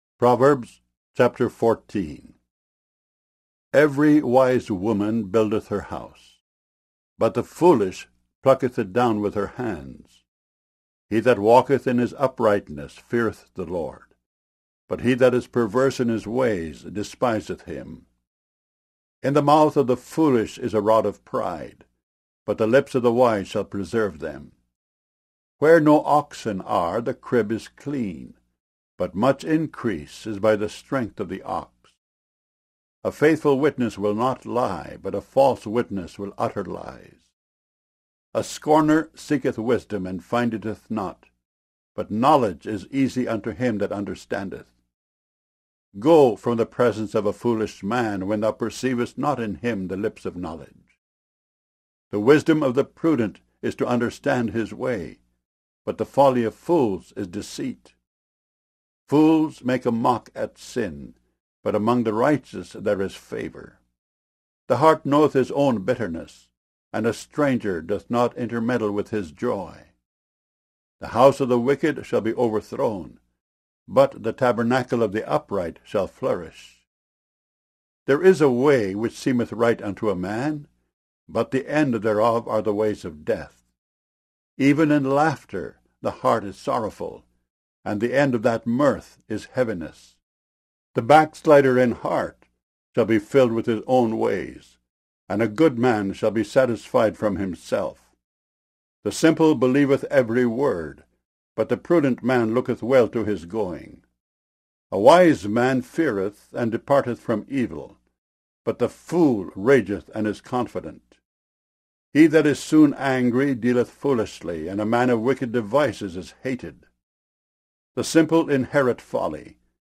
KJV Bible Chapters Mono MP3 64 KBPS